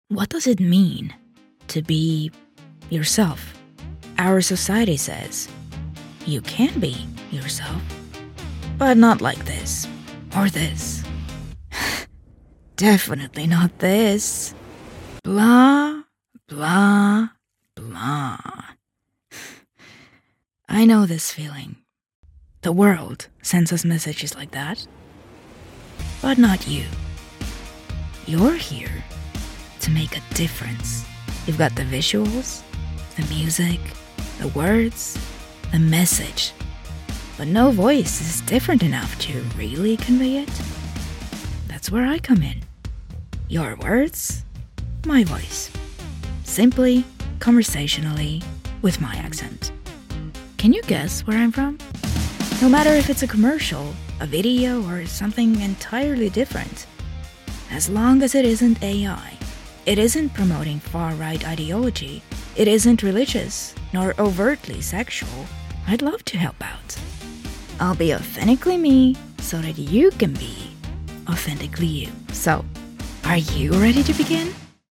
Commercial Demo
My English is crip, clear with great enunciation. My accent is just slight enough to be clearly understandable while also fitting when you’re wanting to connect with people all over.
As a native Estonian speaker, I typically have a slight accent.